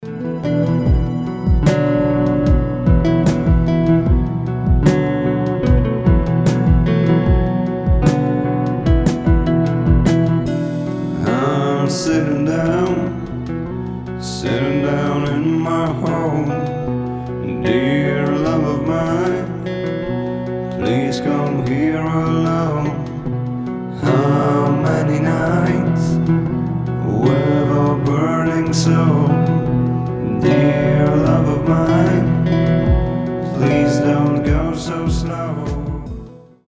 • Качество: 320, Stereo
красивый мужской голос
мелодичные
спокойные
alternative
Soft rock
легкий рок
саундтрек